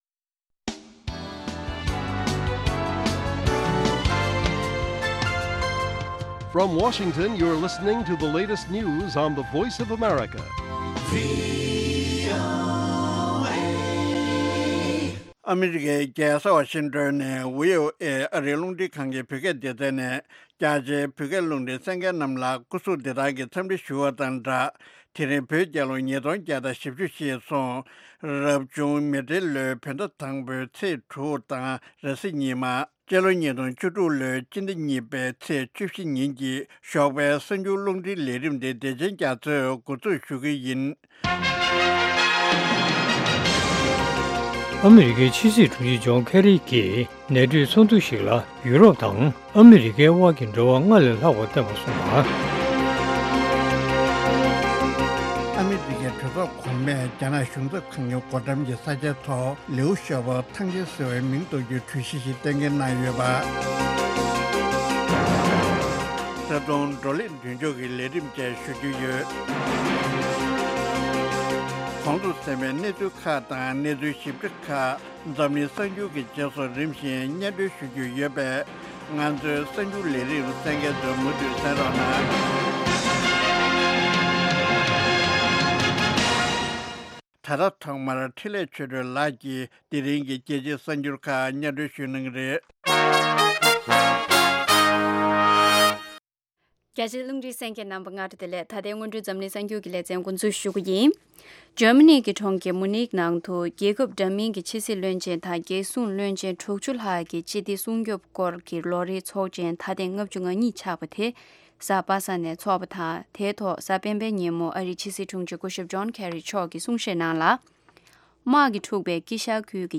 Morning News Morning News Broadcast daily at 08:00 AM Tibet time, the Morning show is a lively mix of regional and world news, correspondent reports, and interviews with various newsmakers and on location informants.